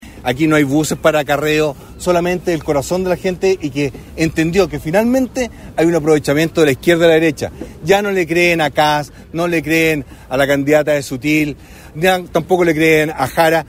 Desde la Plaza Independencia, en el centro de Concepción, y rodeado por alrededor de un centenar de adherentes, el postulante del Partido de la Gente (PDG) presentó algunas de sus propuestas, entre las que figura la baja de sueldos en la administración del estado, la devolución del IVA en los medicamentos y el término de las devoluciones de gasto electoral.